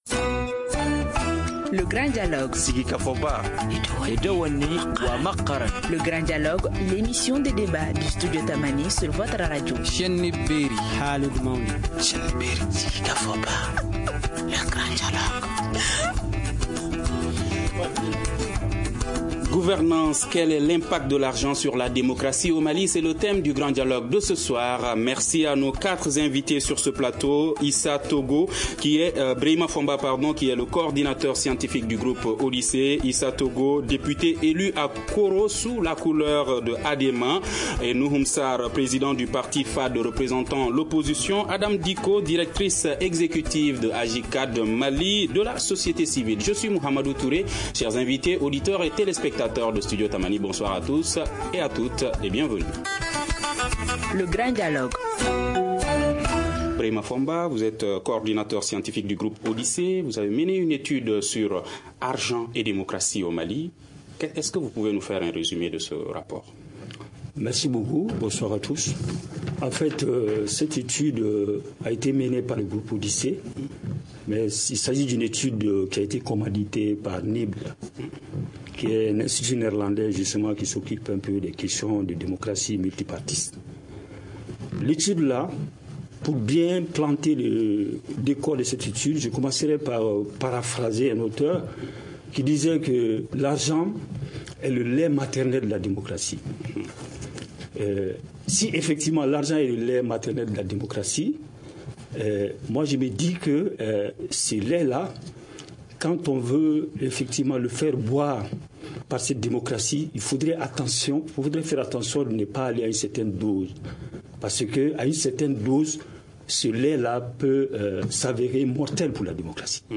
Face à cette situation, le Grand Dialogue de Studio Tamani reçoit des acteurs des partis politiques et de la société civile pour poser le débat.